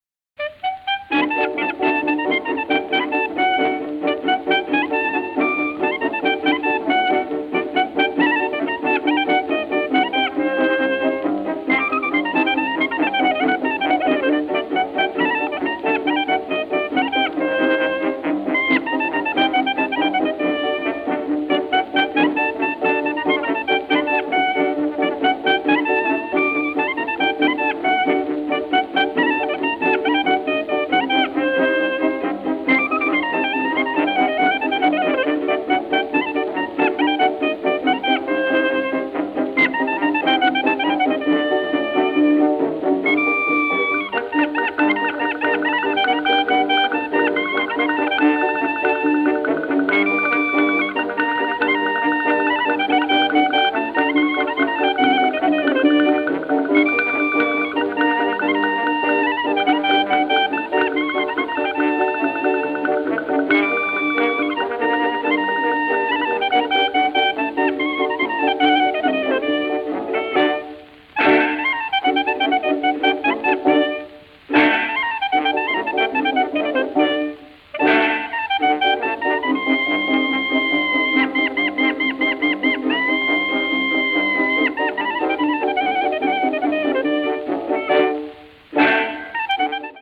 クラリネット奏者